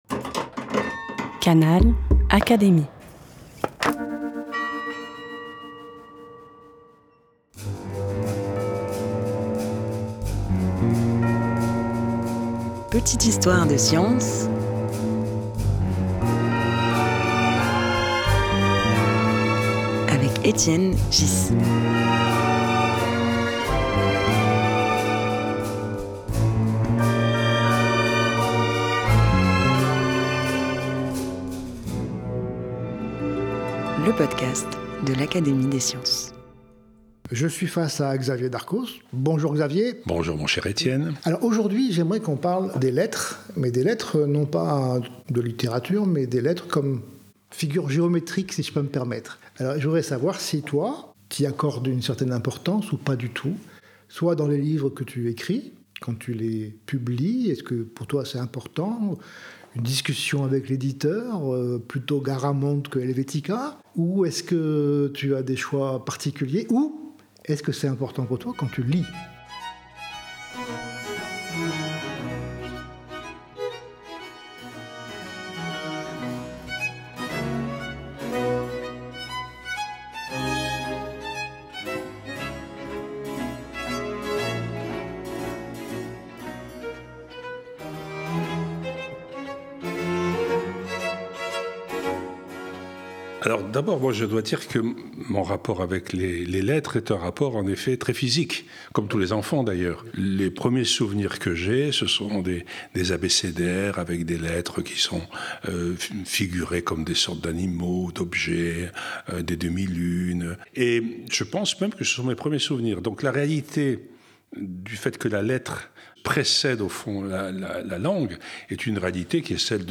La lettre comme pouvoir et comme culture visuelle Au fil de l’entretien, Xavier Darcos montre combien la lettre dépasse son usage pratique pour devenir un outil politique, esthétique ou identitaire.